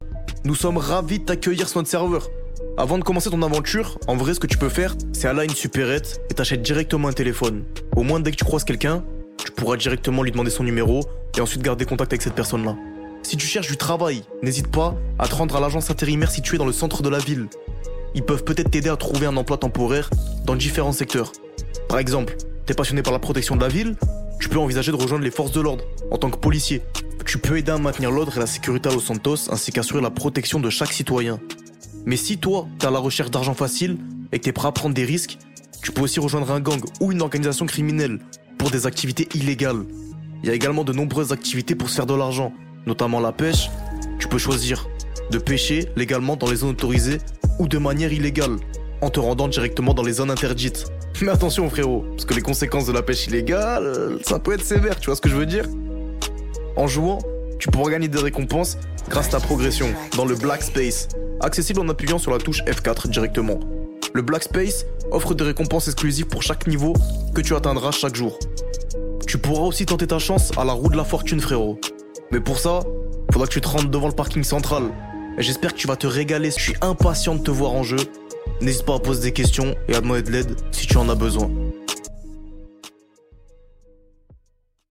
voixoff.mp3